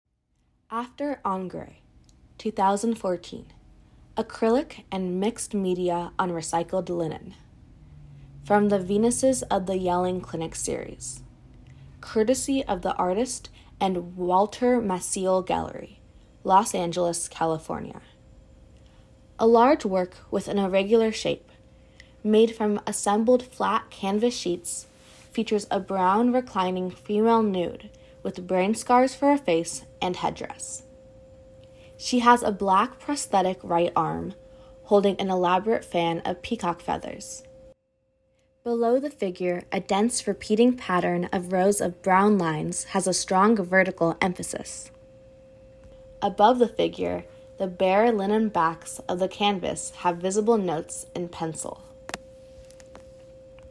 Audio description